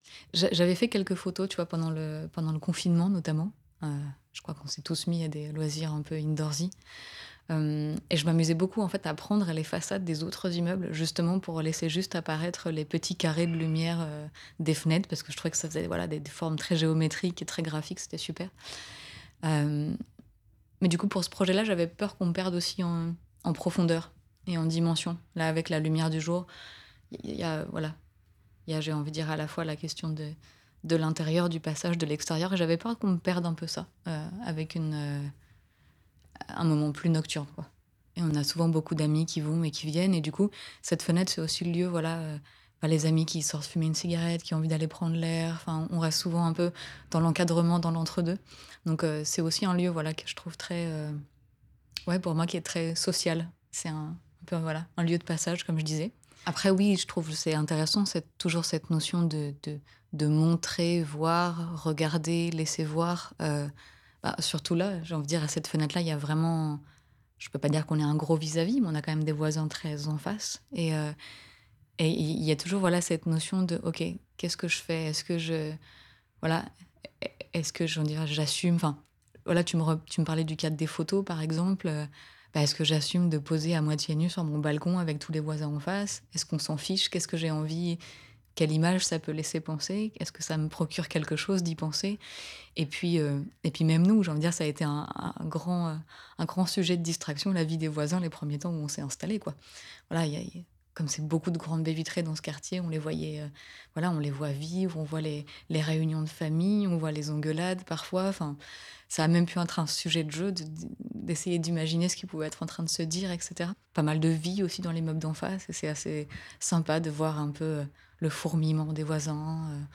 Lyon 6e.